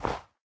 snow3.ogg